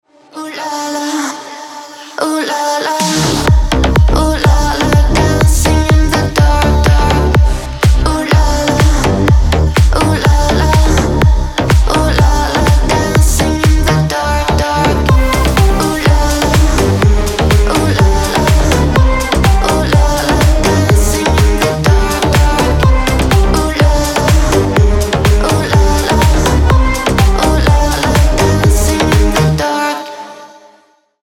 Стиль: brazilian bass